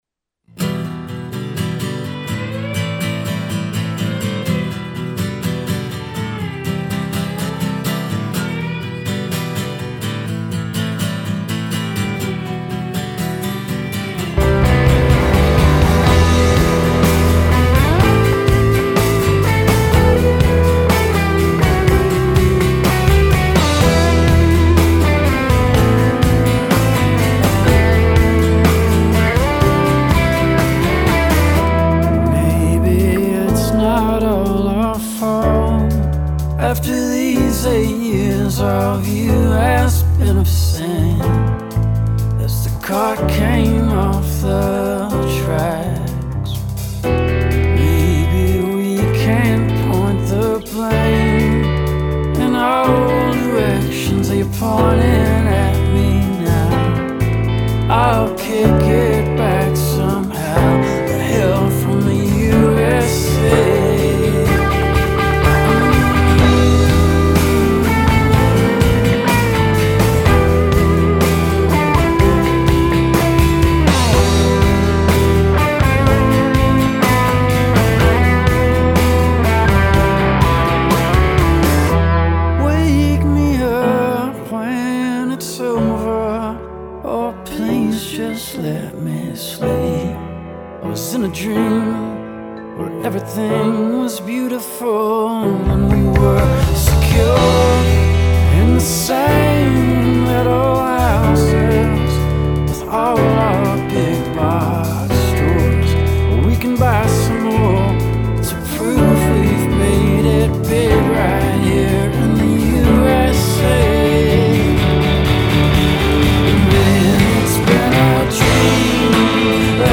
a truly lovely tune
marvelously aching voice